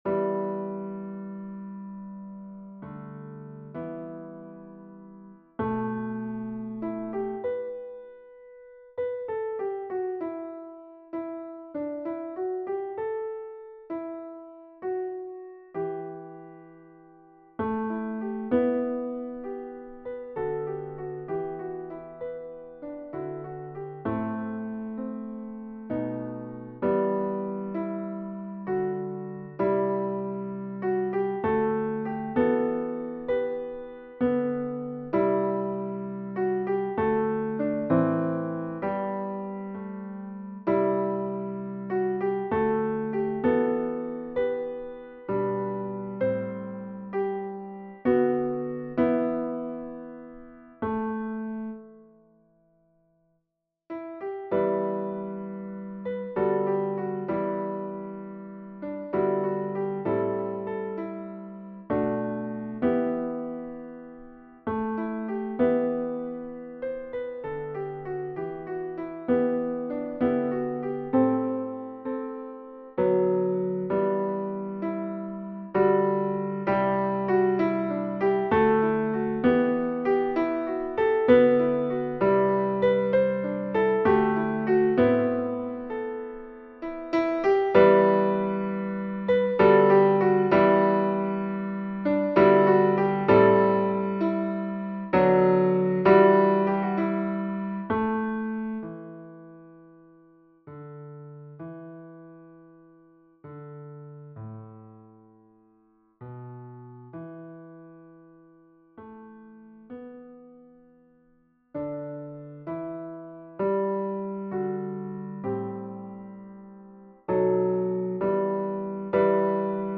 Versions "piano"
Baryton